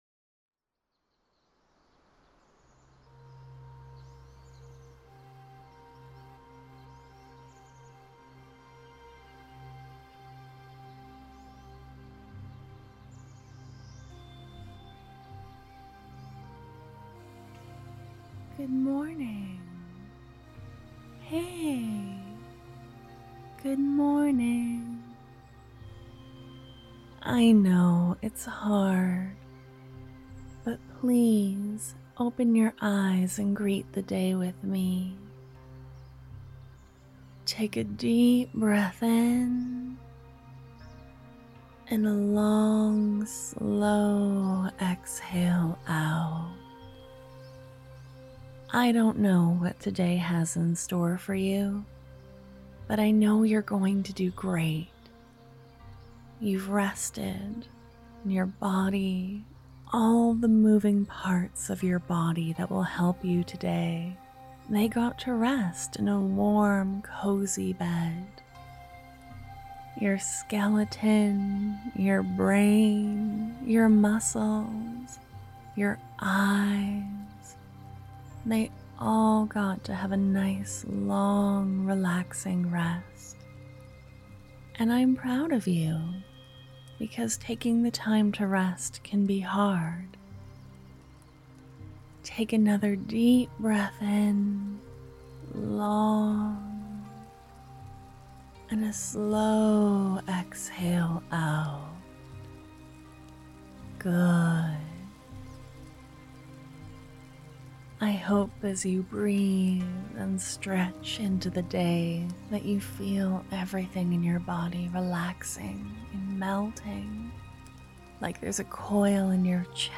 Here is your last alarm for the month~!! It has a slow lead in and long lead out, with some kind affirmations and breathing.